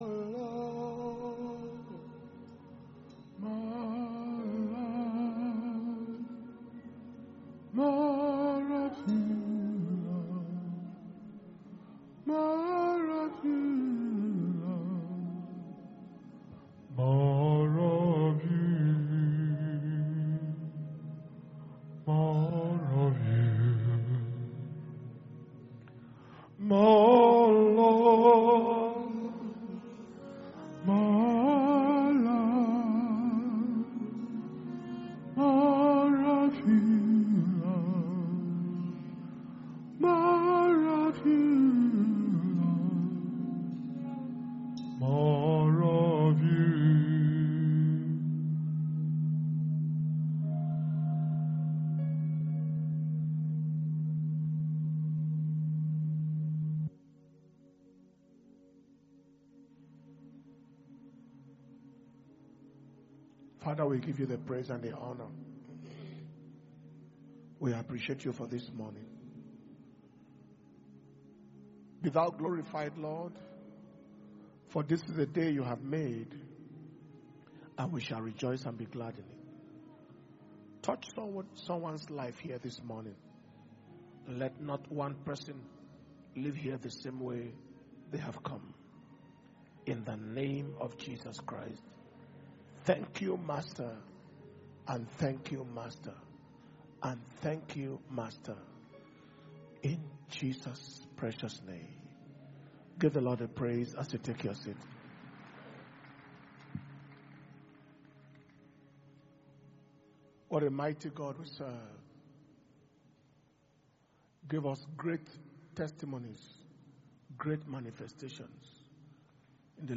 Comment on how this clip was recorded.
April 2021 Impartation Service Message